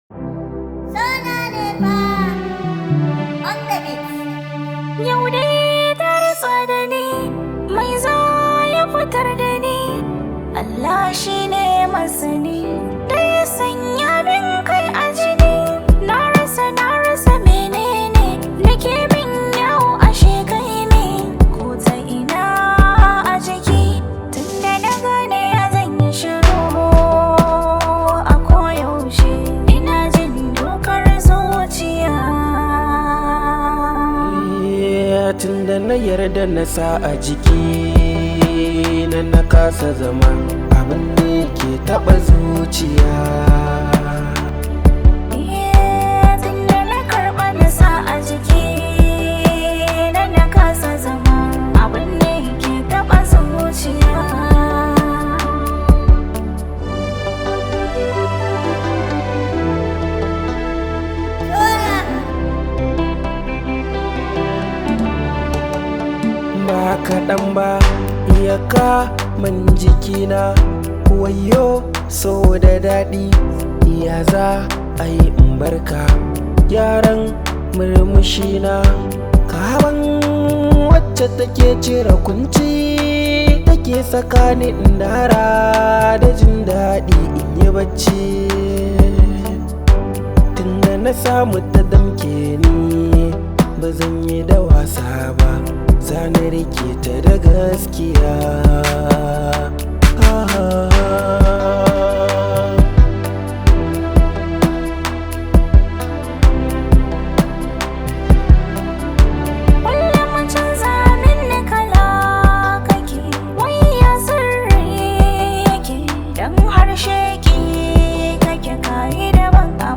hausa song
high vibe hausa song